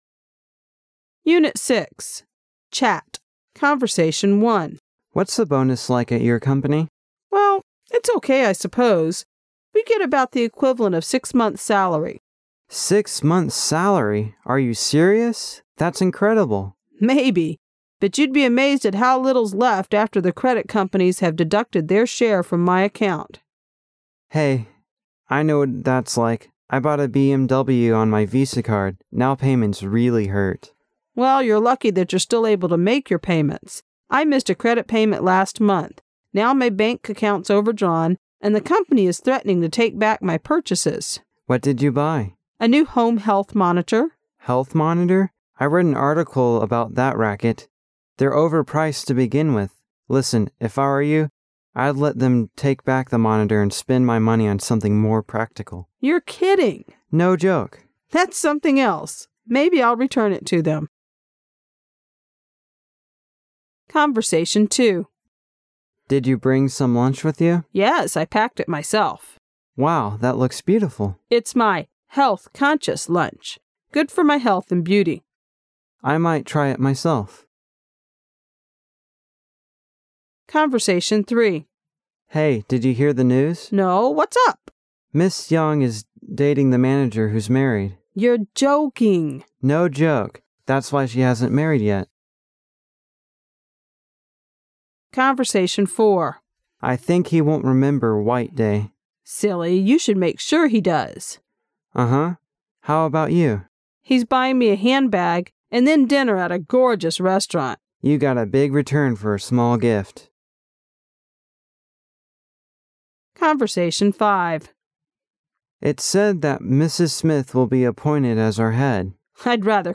Chat